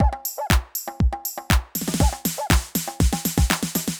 ITA Beat - Mix 1.wav